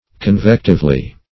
convectively - definition of convectively - synonyms, pronunciation, spelling from Free Dictionary Search Result for " convectively" : The Collaborative International Dictionary of English v.0.48: Convectively \Con*vec"tive*ly\, adv.
convectively.mp3